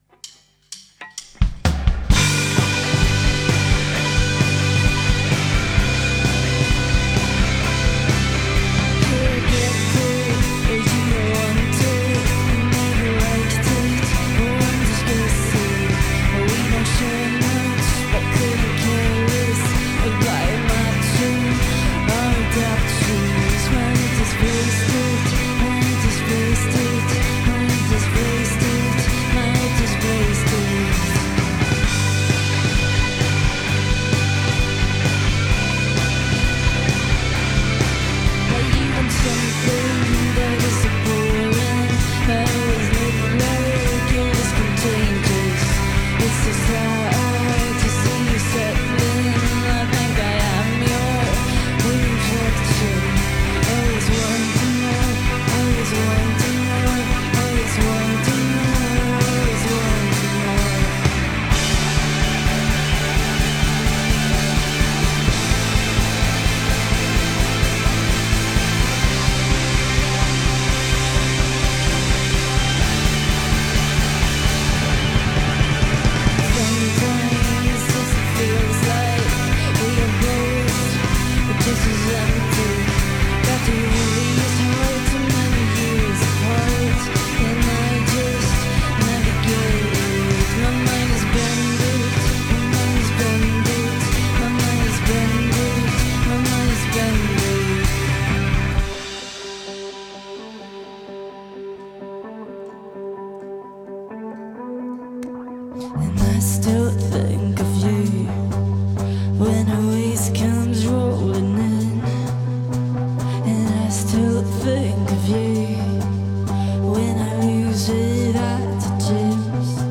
The effortless and dreamy mix of fuzz, pop and Indie.